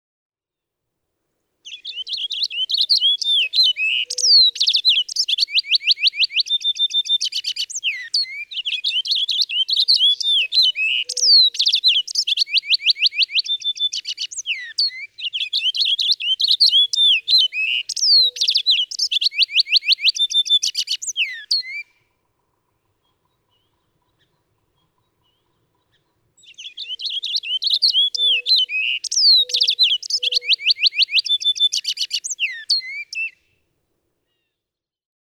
Purple finch
Brilliant, highly polished, rich and mellow, bright and lively . . . In flight, he sings continuously; inspection of a sonagram shows that he has a remarkable seven-second song that he repeats four times in this selection.
Mountain Road, Whately, Massachusetts.
658_Purple_Finch.mp3